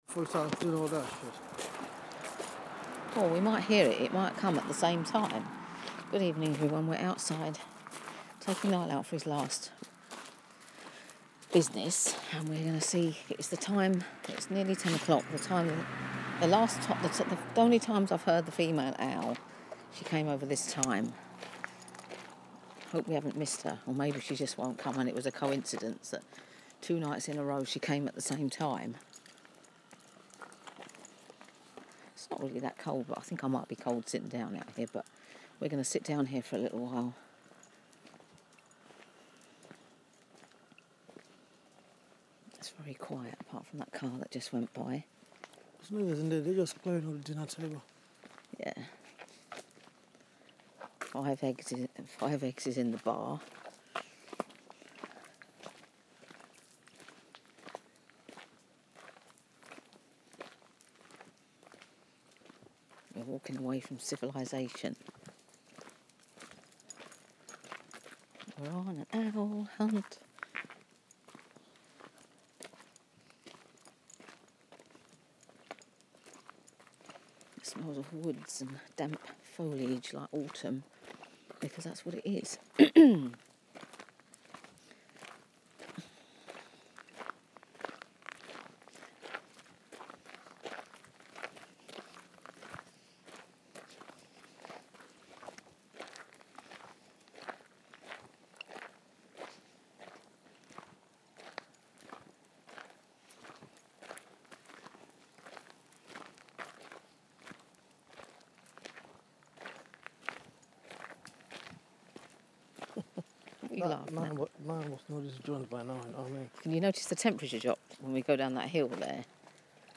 Outside listening for owls